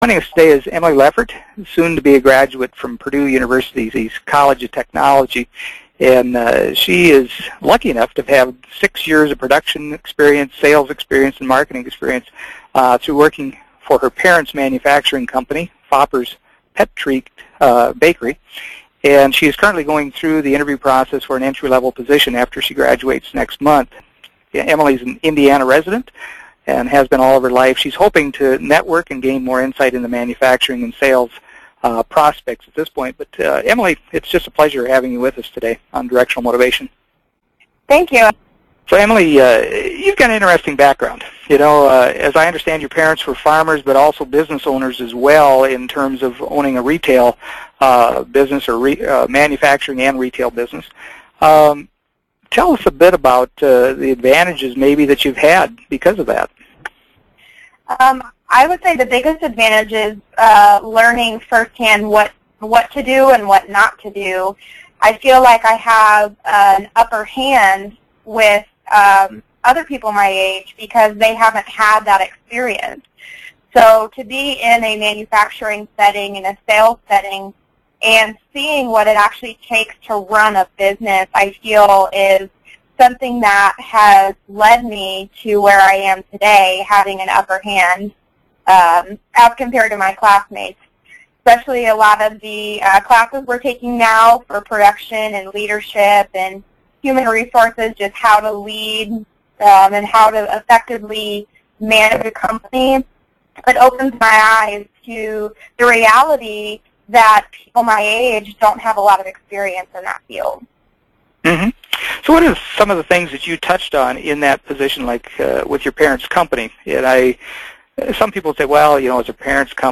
Executive Leadership Interviews